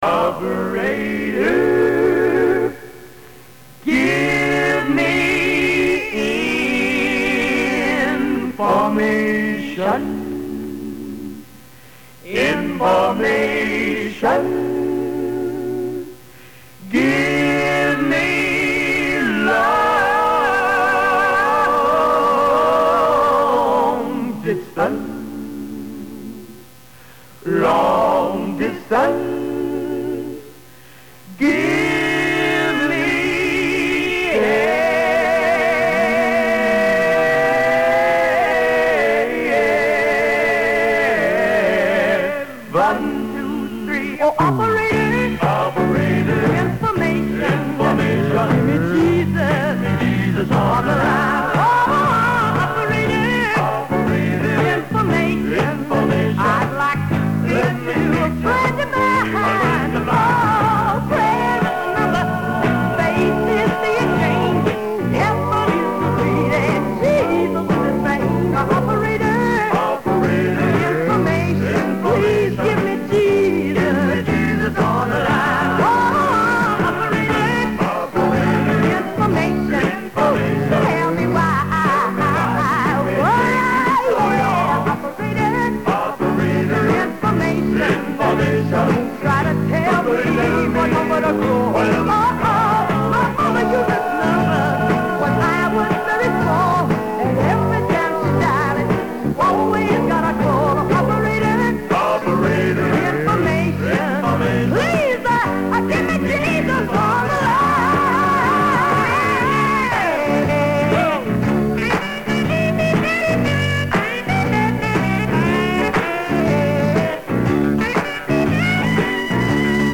working the Night Shift for AFTN Korat in 1975
KoratAFTNOnAir1975-6.mp3